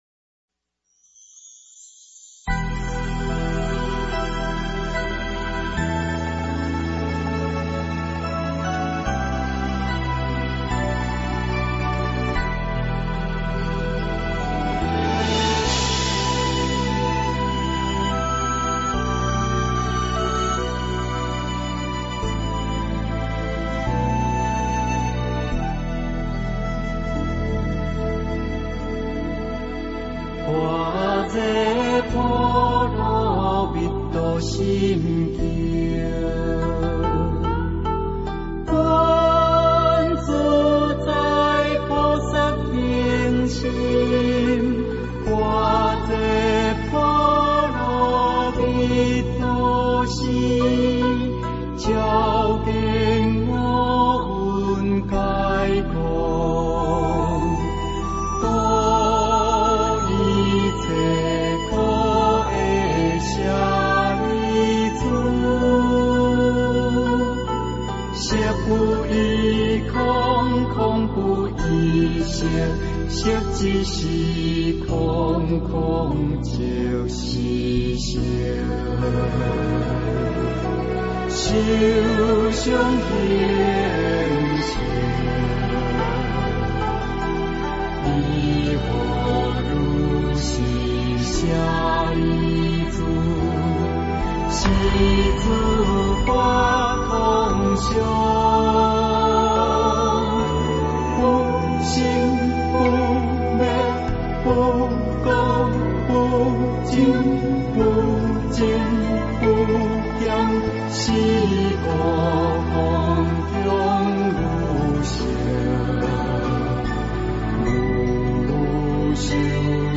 佛音 诵经 佛教音乐 返回列表 上一篇： 心经（唱诵） 下一篇： 心经 相关文章 泛音--空雨 泛音--空雨...